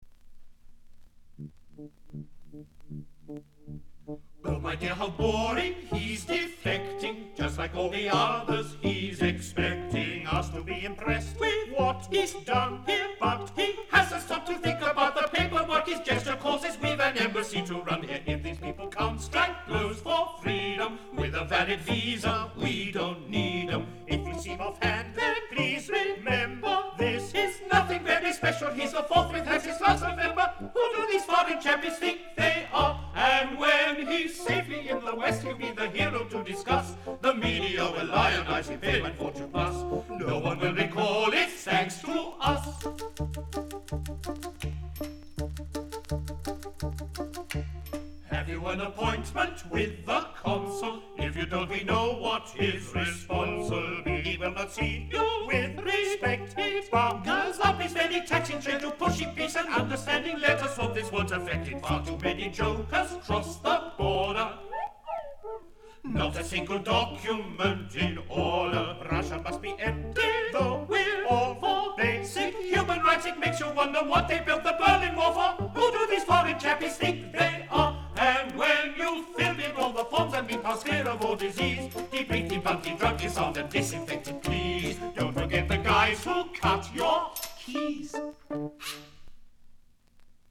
Жанр: Electronic, Rock, Pop, Stage & Screen
Стиль: Pop Rock, Musical, Ballad, Synth-pop